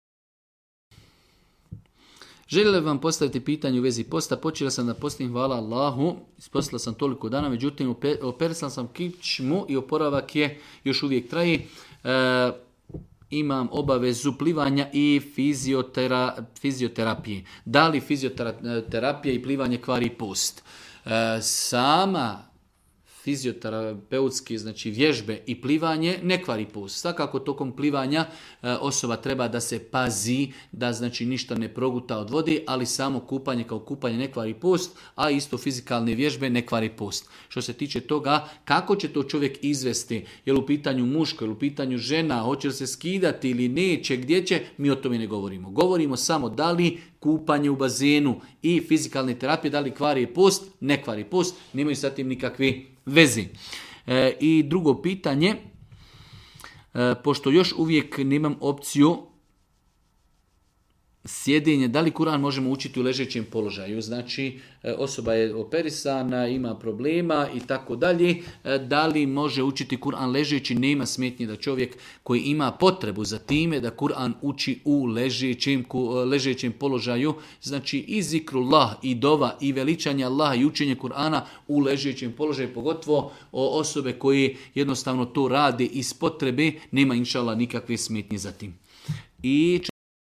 u video predavanju.